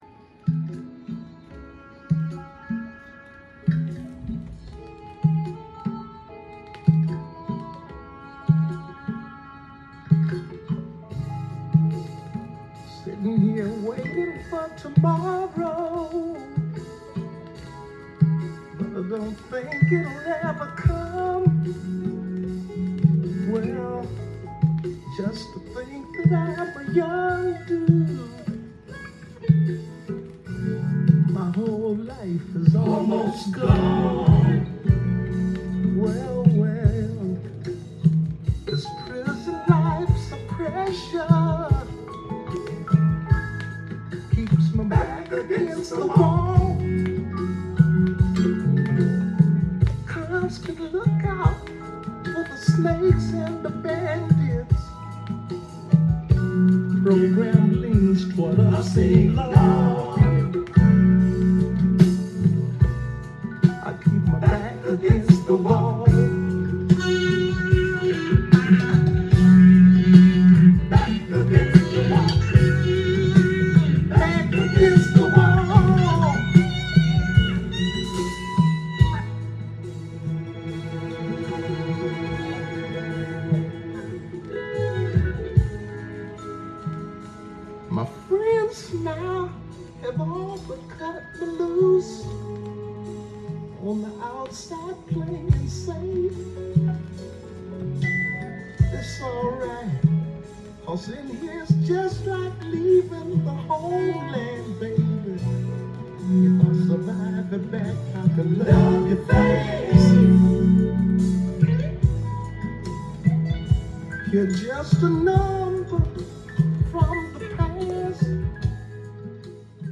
ジャンル：FUSION
店頭で録音した音源の為、多少の外部音や音質の悪さはございますが、サンプルとしてご視聴ください。
妖艶なメロウ